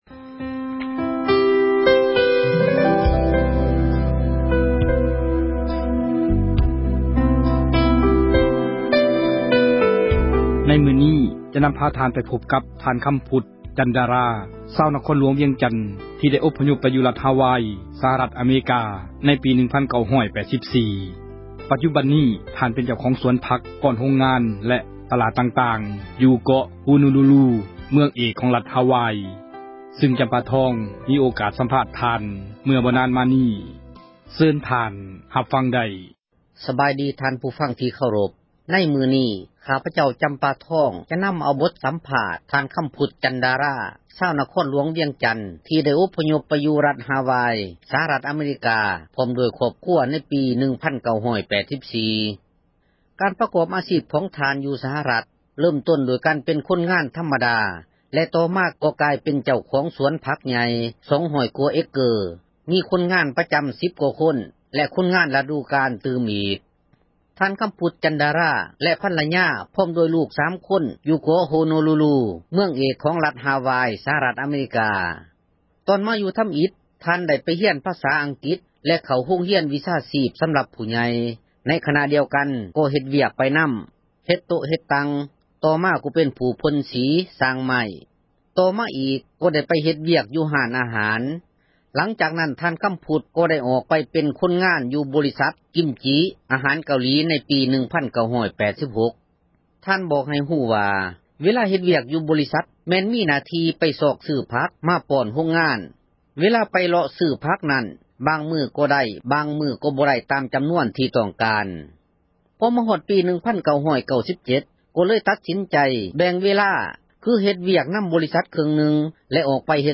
ສັມພາດນັກທຸຣະກິຈ ເຈົ້າຂອງສວນຜັກ